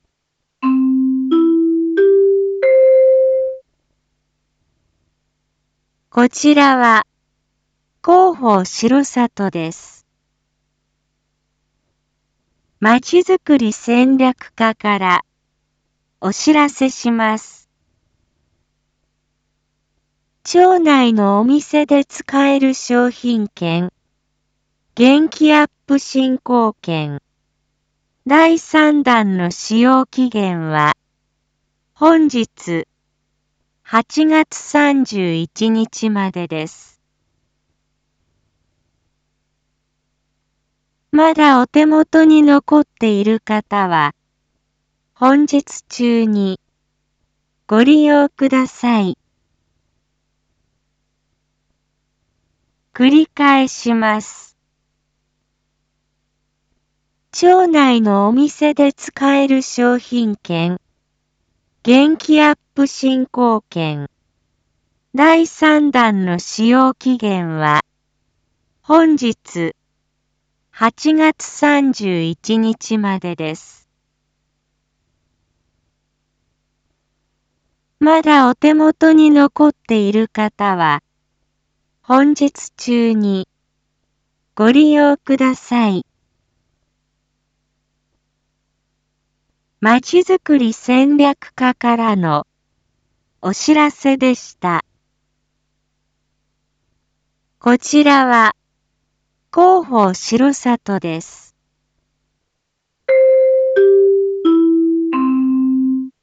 一般放送情報
Back Home 一般放送情報 音声放送 再生 一般放送情報 登録日時：2021-08-31 07:01:49 タイトル：R3.8.31、7時 インフォメーション：こちらは、広報しろさとです。